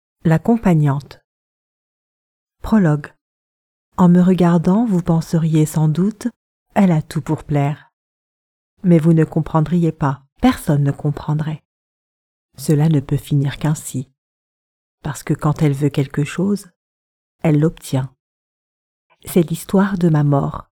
Never any Artificial Voices used, unlike other sites. All our voice actors are premium seasoned professionals.
Adult (30-50) | Yng Adult (18-29)